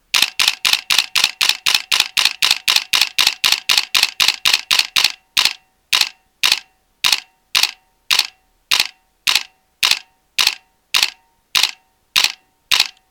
Nikon D800 sorozat hangja (MP3)